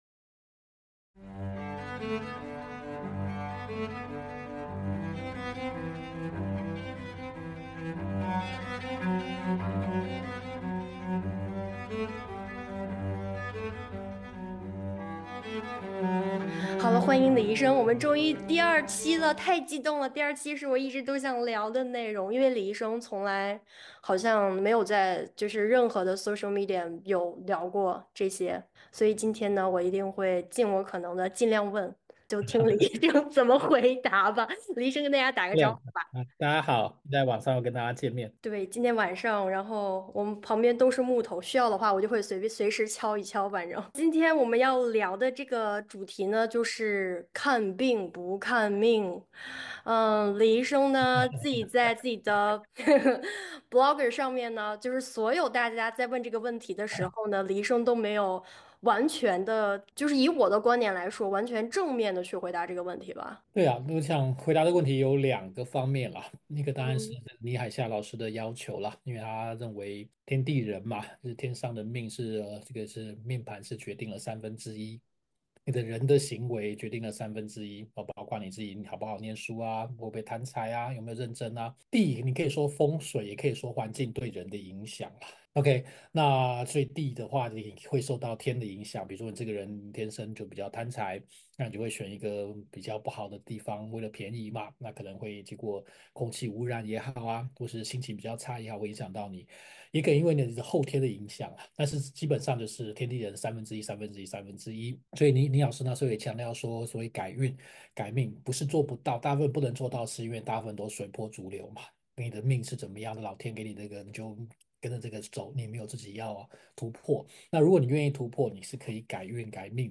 目前沒有特定的題材，也沒有特定的時間表，隨性也隨時間，藉由主持人的提問，來和大家聊一聊。